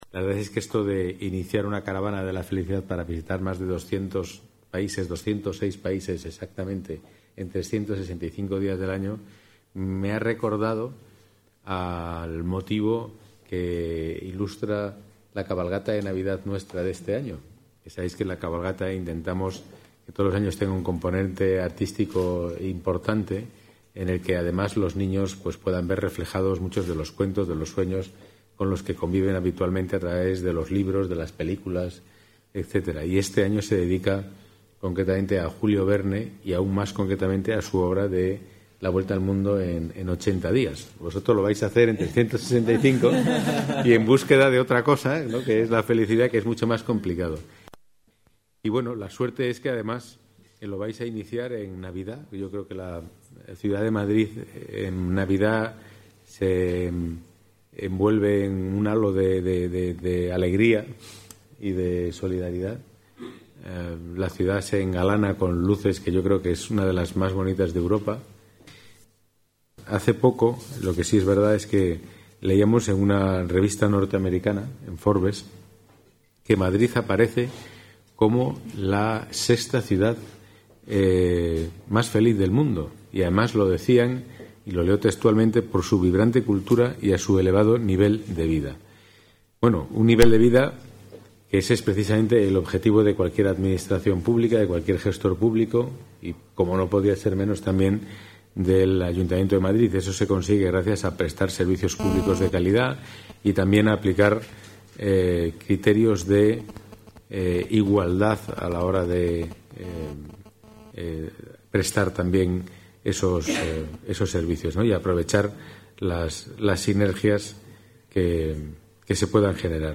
Nueva ventana:Declaraciones de Pedro Calvo, delegado de Seguridad: Caravana de la Felicidad